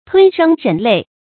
吞聲忍淚 注音： ㄊㄨㄣ ㄕㄥ ㄖㄣˇ ㄌㄟˋ 讀音讀法： 意思解釋： 形容強忍悲傷。